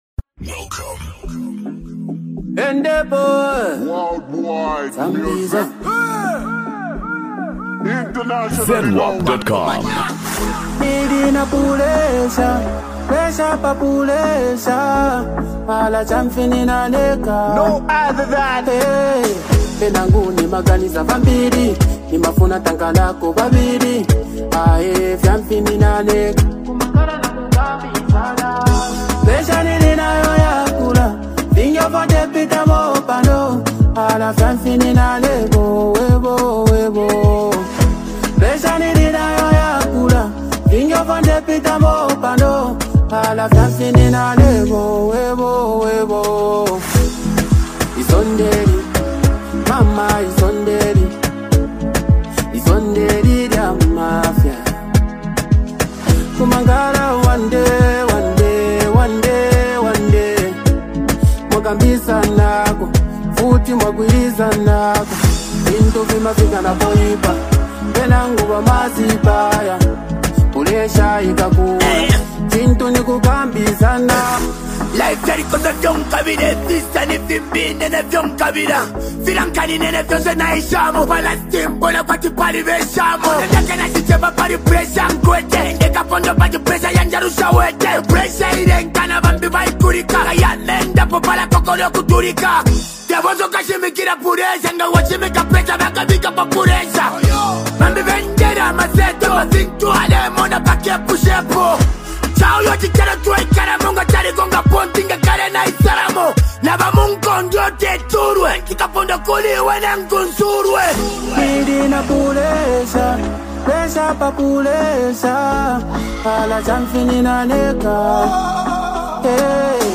Genre: Afro-beats, Zambia Songs